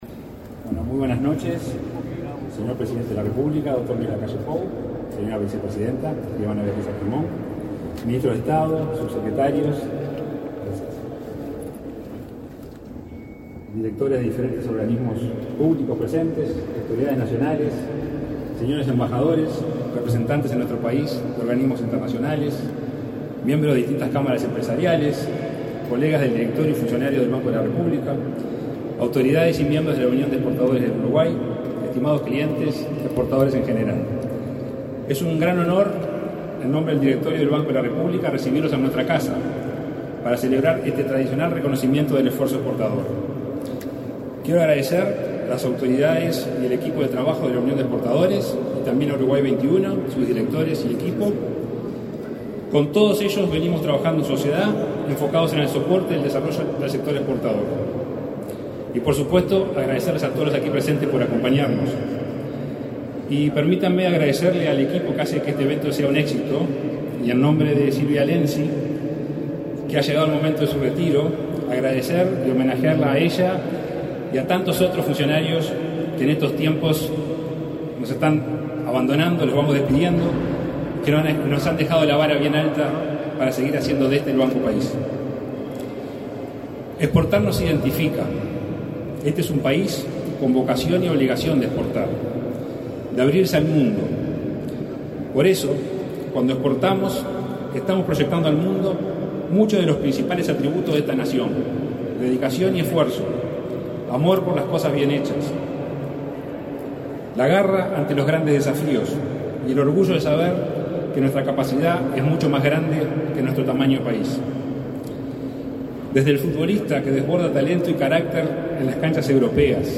Con la presencia del presidente de la República, Luis Lacalle Pou, se realizó, este 13 de diciembre, la entrega de Premios al Esfuerzo Exportador,